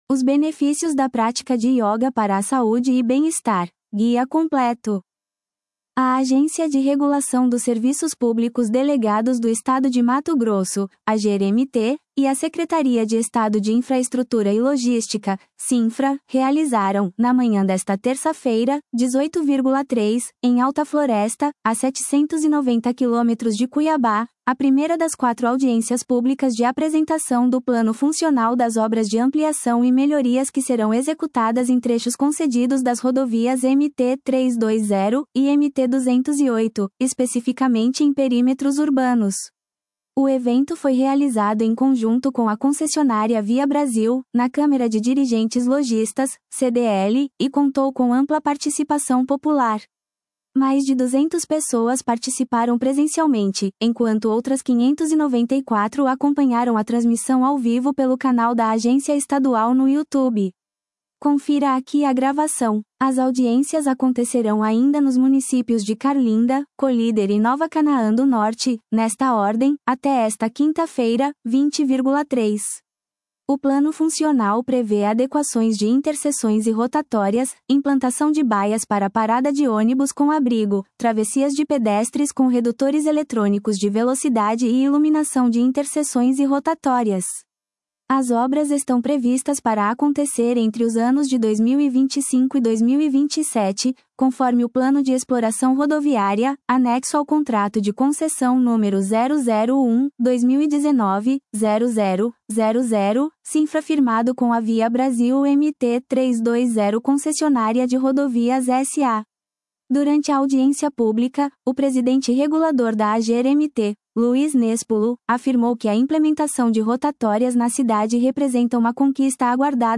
Audiência pública é realizada em Alta Floresta para apresentar plano de melhorias nos perímetros urbanos das MTs 320 e 208 -